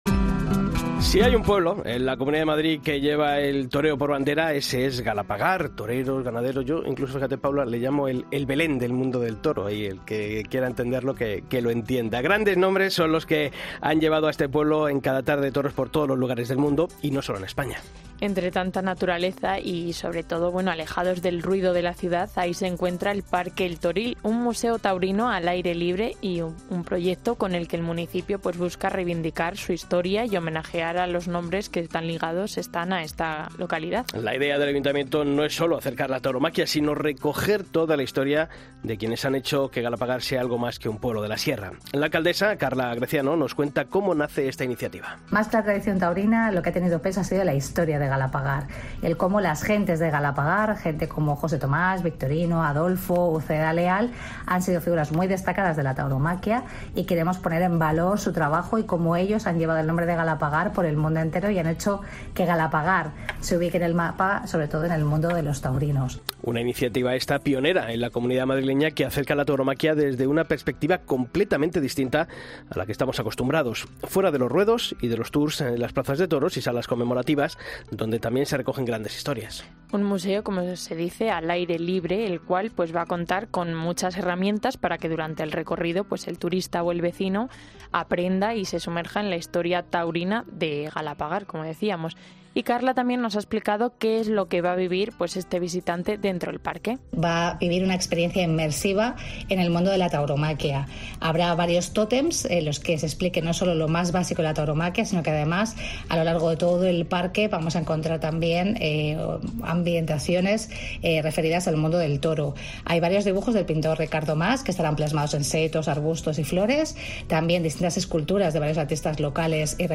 La alcaldesa de Galapagar, Carla Greciano, detalla el proyecto que convertirá al Parque El Toril en un Museo Taurino al aire libre, pionero en la Comunidad de Madrid